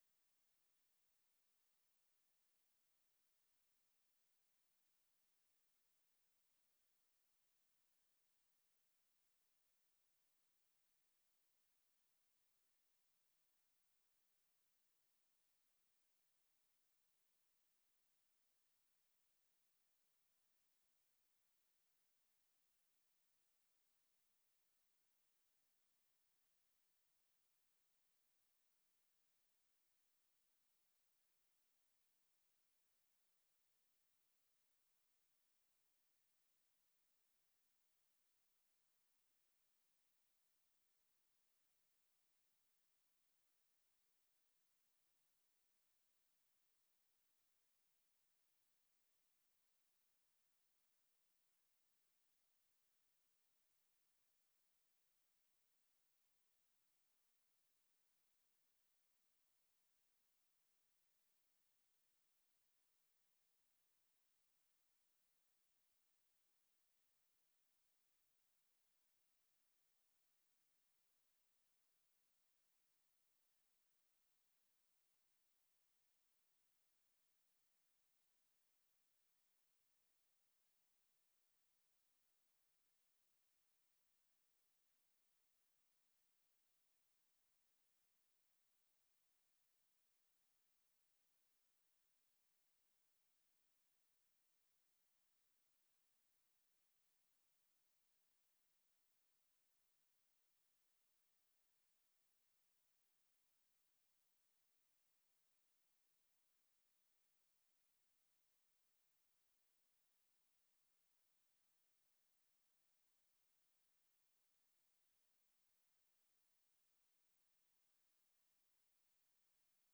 We play a silent sound to prevent app nap and network disconnections.
prevent-app-nap-silent-sound.aiff